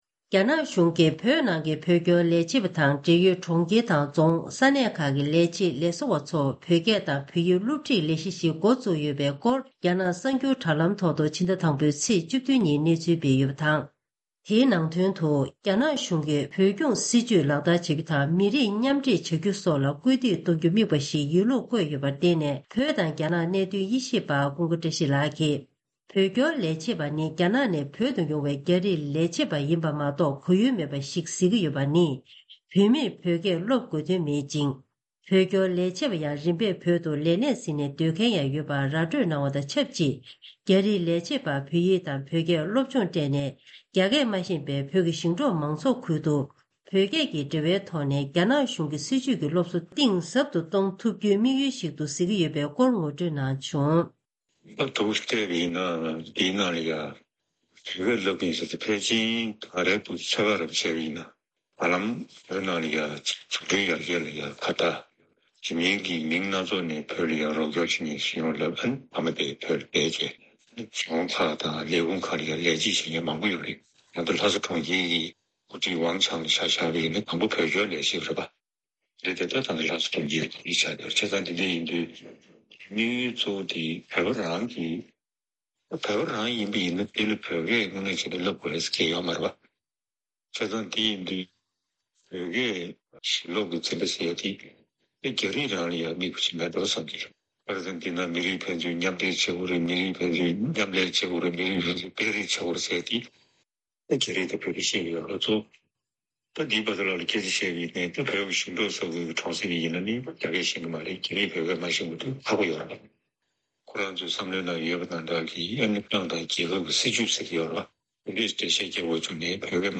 ཐེངས་འདིའི་གསར་འགྱུར་དཔྱད་གཏམ་གྱི་ལེ་ཚན་ནང་།